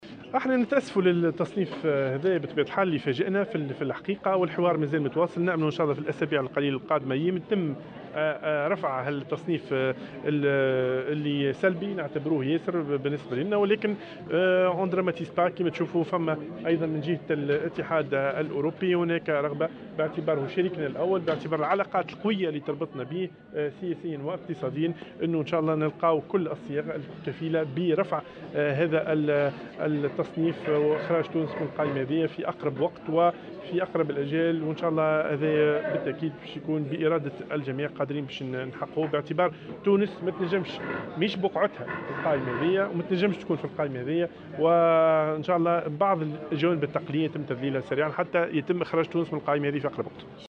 وأضاف العذاري في تصريح لمراسلة "الجوهرة أف أم" على هامش المؤتمر السنوي الخامس حول المسؤولية المجتمعية للمؤسسات في تونس، أن هناك مساع مع الاتحاد الأوروبي الذي تربطه مع تونس علاقات متينة لإيجاد كل الصيغ الكفيلة برفع هذا التصنيف وإخراج تونس من هذه القائمة في أقرب الآجال.